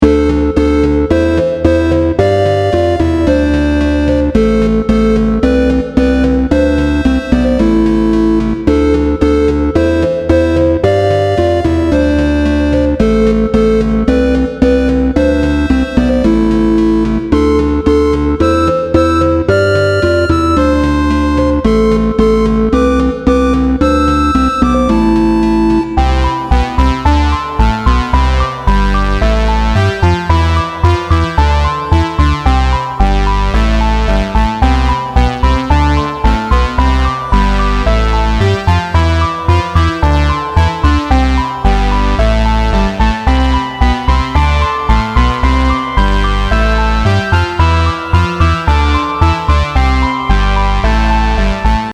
Song I'd intended to use as the backdrop of a character's tragic story of origin.